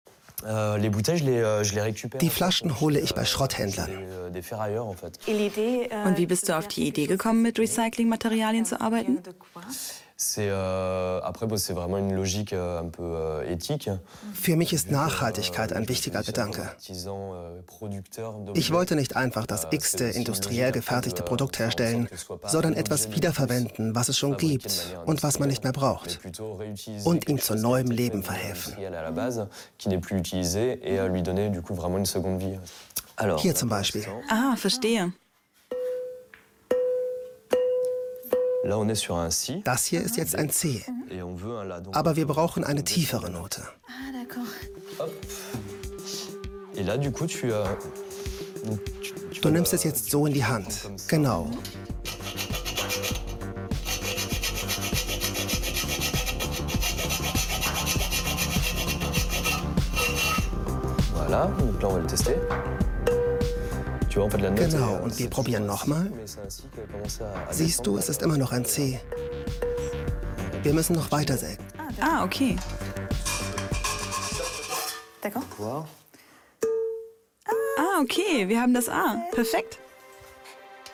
Equipment: Soundblocker Sprecher - Box Mikro Neumann TLM 103 Channelstrip focusrite ISA 220 Wandler Mini - Me von Apogee m-box Muttersprache: deutsch und spanisch
Sprechprobe: eLearning (Muttersprache):